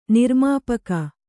♪ nirmāpaka